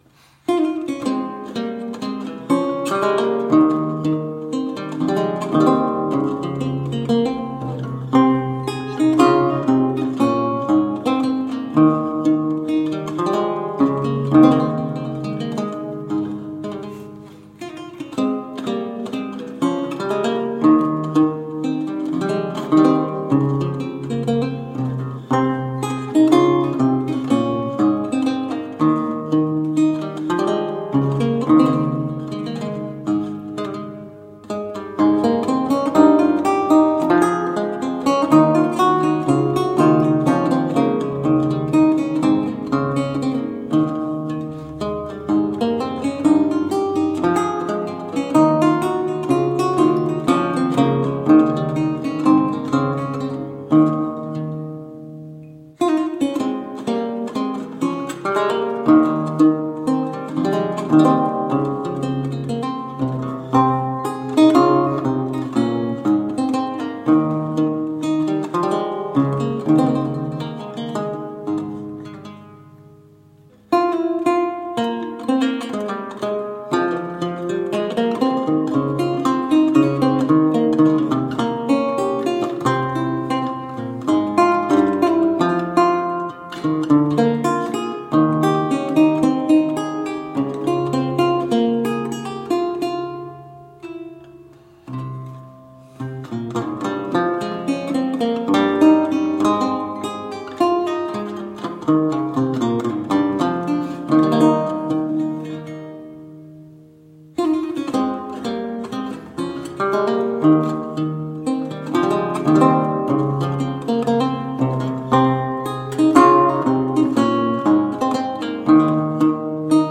Vihuela, renaissance and baroque lute
Classical, Baroque, Renaissance, Instrumental
Lute